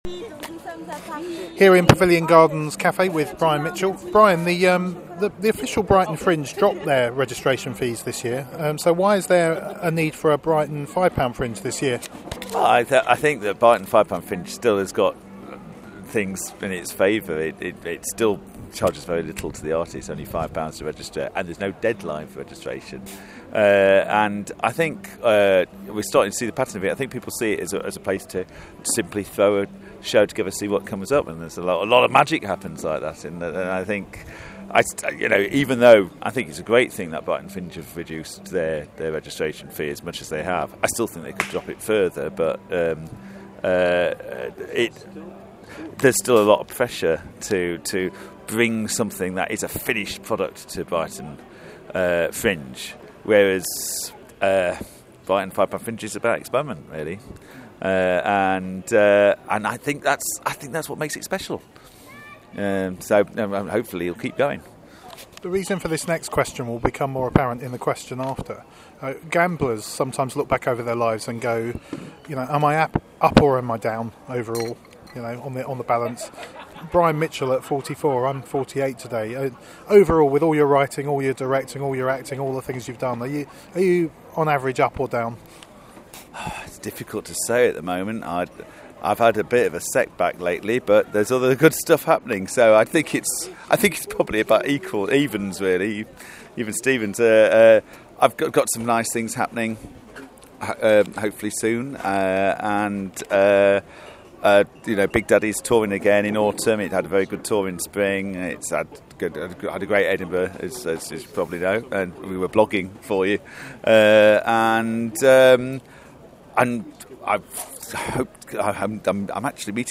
Audio Interviews, Brighton Fringe 2014